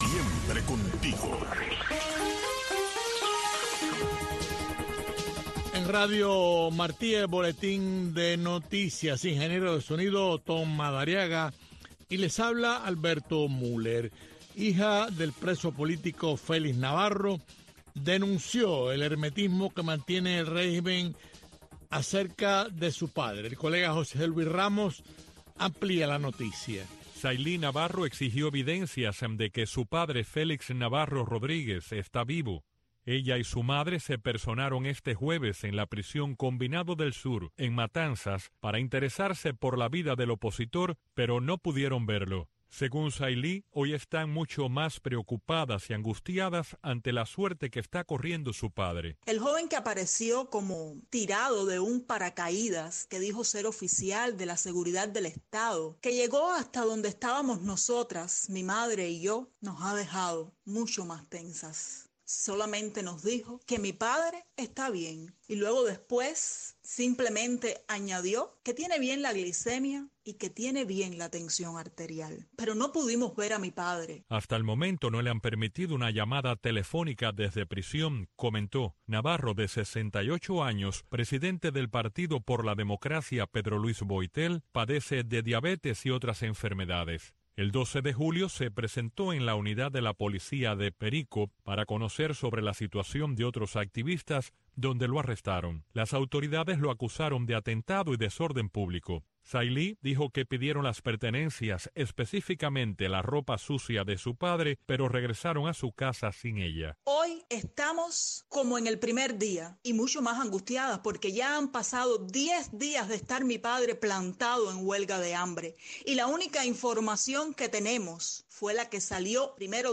Entrevistas e informaciones con las voces de los protagonistas desde Cuba. Servirá de enlace para el cubano conozca lo que sucede en el país sin censura.